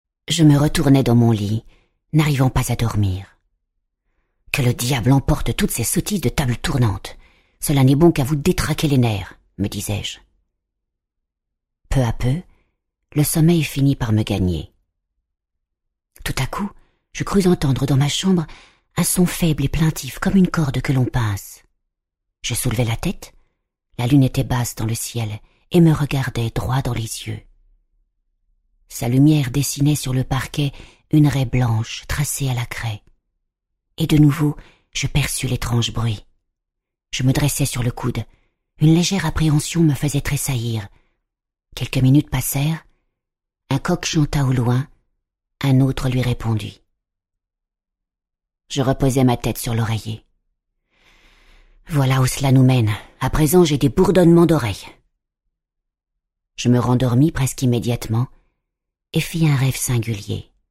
Click for an excerpt - Fantômes de Ivan Tourgueniev